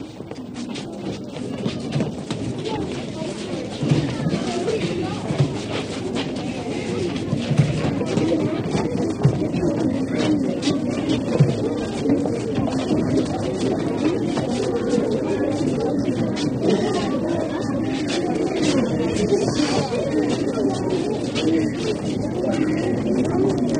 Student Walla
School Corridor Footsteps And Shuffle